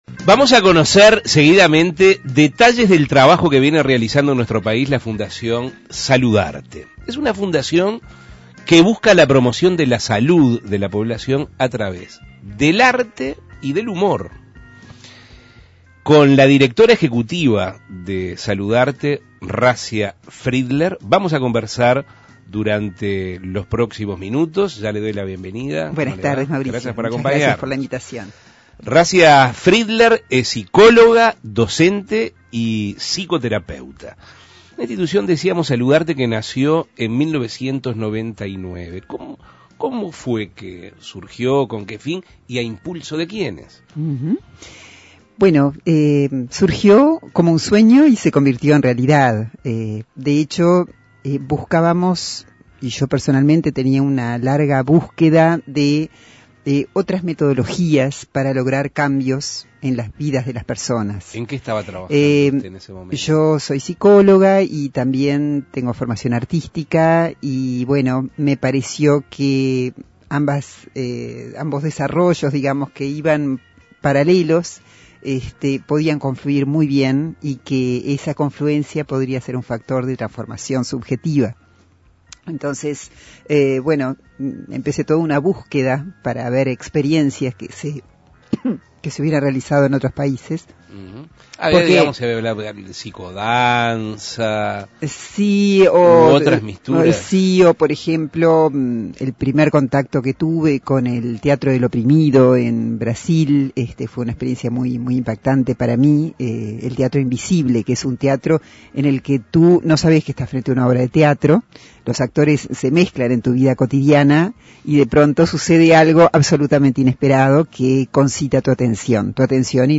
Entrevistas La salud a través del arte Imprimir A- A A+ La fundación Saludarte busca la promoción de la salud de la población a través del arte y el humor.